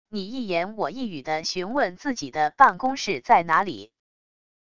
你一言我一语的询问自己的办公室在哪里wav音频